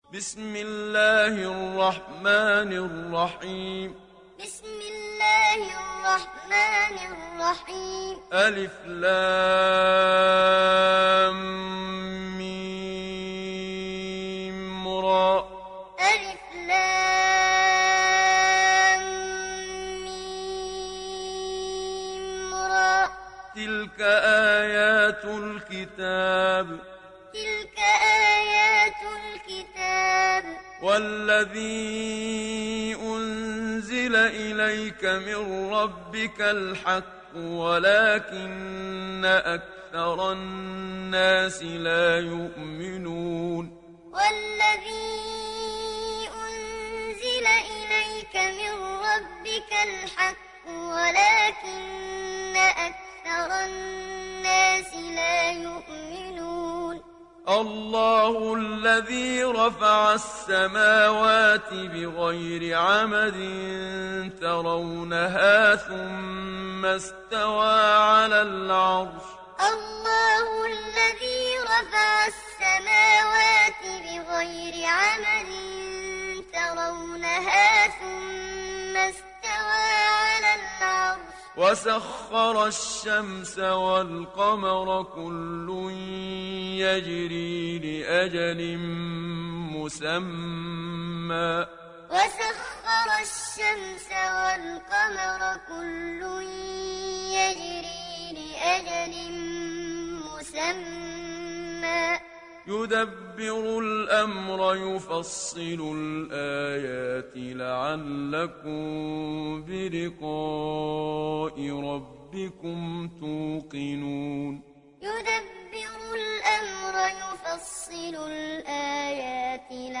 دانلود سوره الرعد محمد صديق المنشاوي معلم